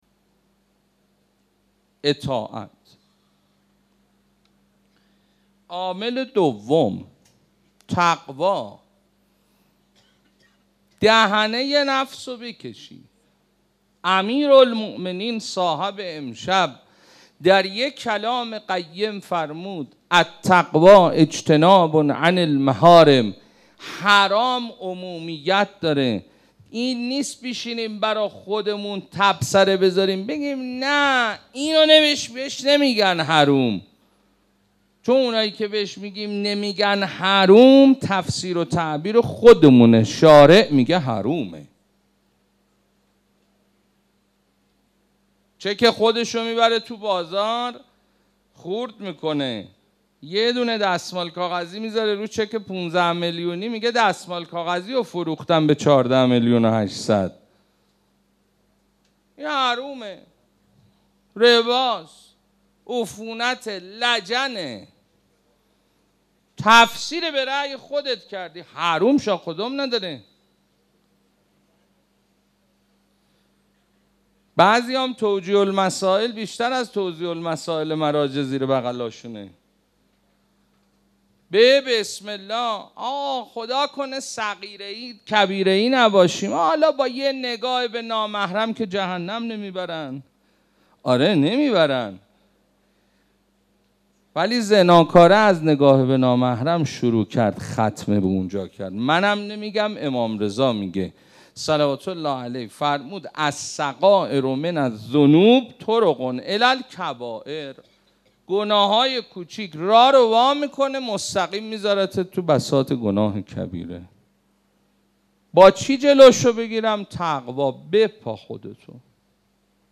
Layer ۱ ریشه > ماه رمضان > مناجات > 1393 > شب شهادت حضرت امیرالمومنین علی B - 21 رمضان 1435 > سخنرانی > 1- ÈÎÔ Çæá